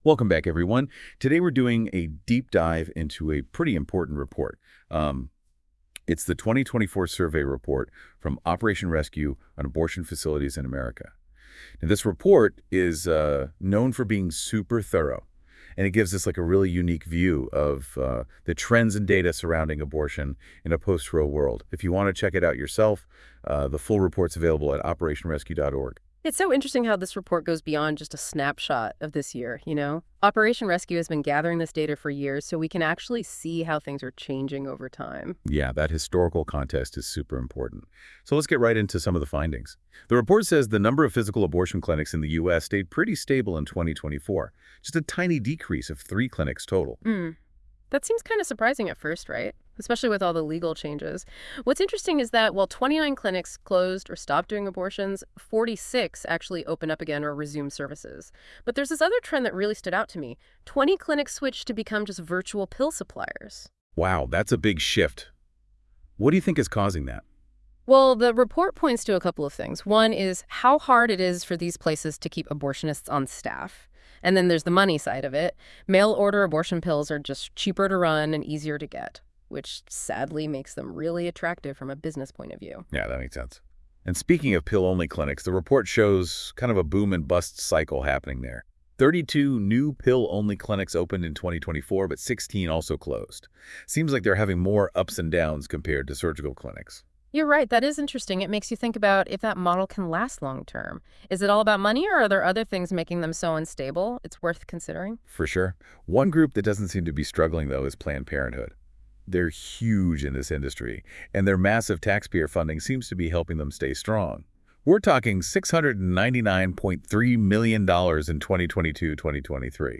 Click on image for computer-generated “DEEP DIVE” conversation into Operation Rescue’s 2024 Annual Survey findings.